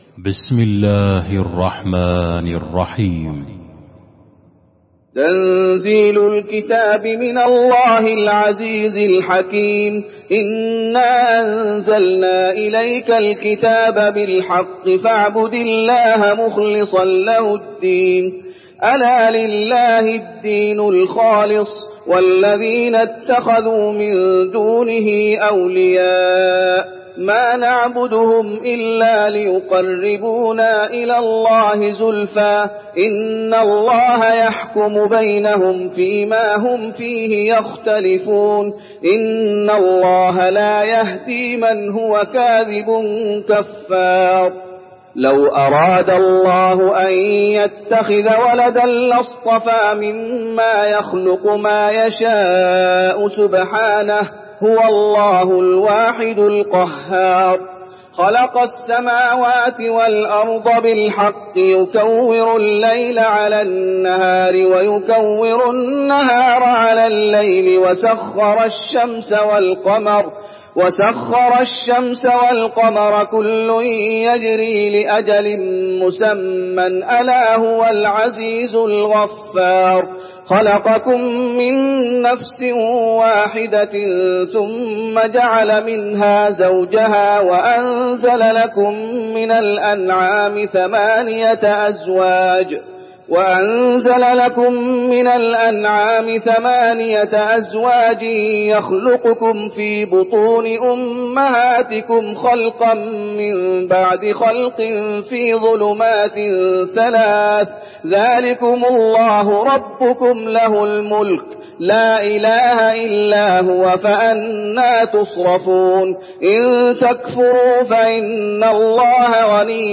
المكان: المسجد النبوي الزمر